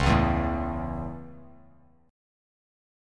C#m#5 chord